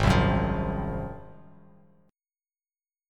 Ab13 chord